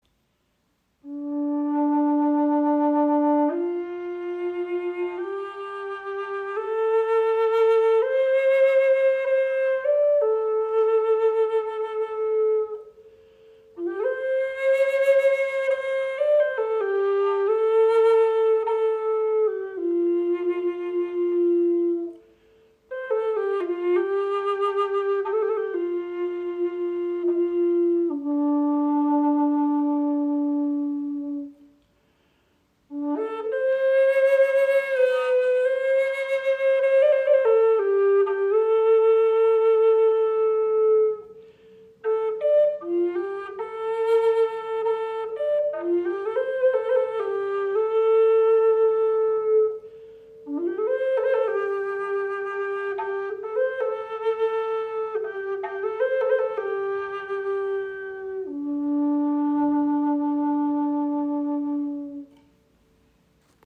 • Icon Gesamtlänge 69 cm, 25 mm Innenbohrung – umarmender Klang
Die Flöte liegt angenehm in der Hand, spricht leicht an und entfaltet einen kraftvollen, lebendigen Bassklang.
Entdecke die handgefertigte Gebetsflöte aus Lacewood mit Ebenholz-Akzenten in D-Moll. Klarer, warmer Bassklang – ideal für Meditation, Rituale, Naturklang und intuitives Spiel.
Lacewood ist ein hartes, edles Tonholz mit feiner Maserung, das der Flöte einen klaren, warmen und meditativen Klang verleiht – präsent, lebendig und zugleich umarmend.
Ihr vollmundiger, zugleich feiner Ton macht sie ideal für Meditation, Rituale, Naturklang und freies, intuitives Spiel.